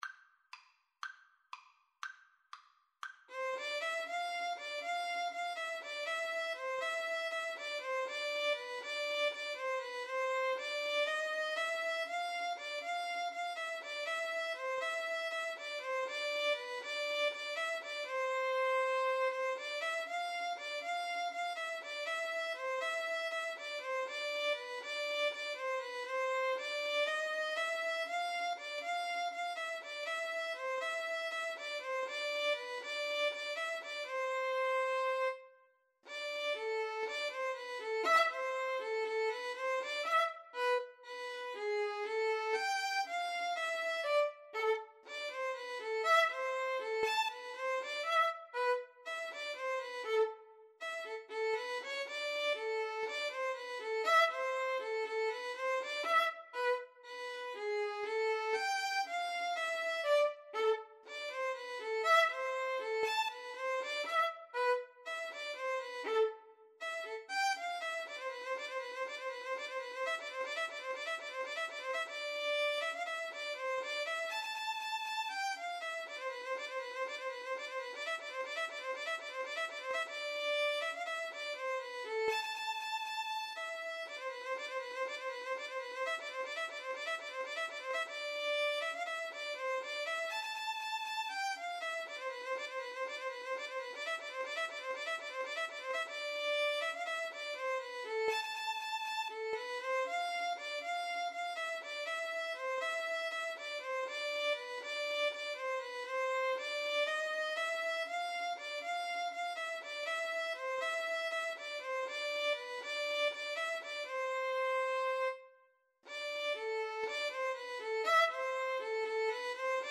A minor (Sounding Pitch) (View more A minor Music for Violin Duet )
Fast Two in a Bar =c.120